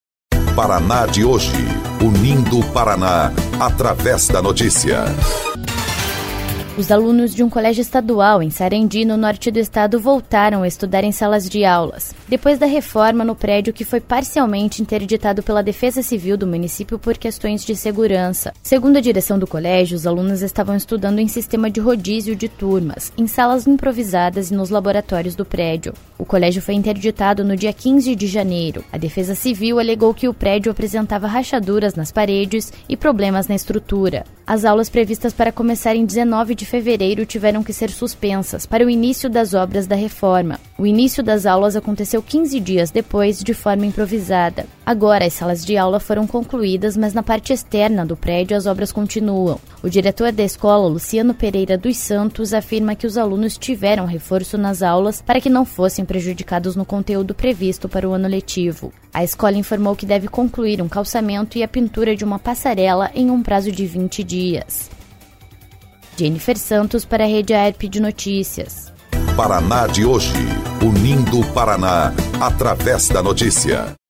19.04 – BOLETIM – Após reforma em prédio, alunos de colégio de Sarandi voltam a estudar em salas de aula